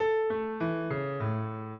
minuet7-6.wav